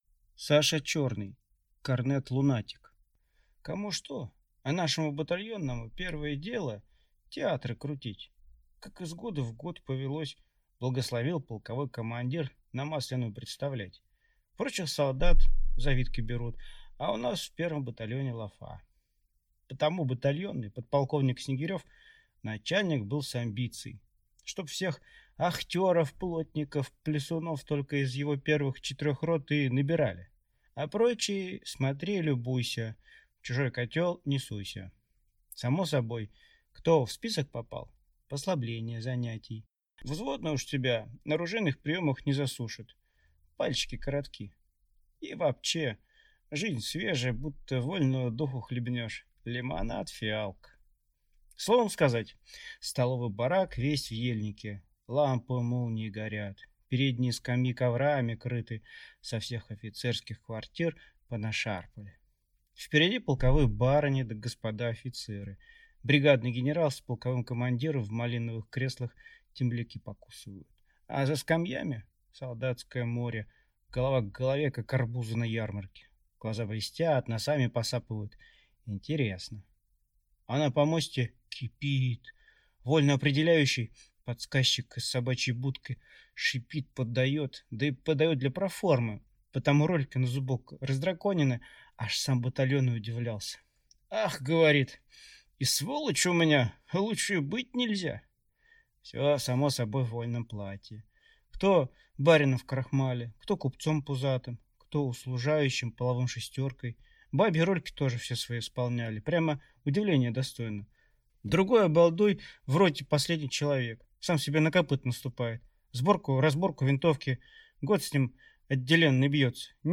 Аудиокнига Корнет-лунатик | Библиотека аудиокниг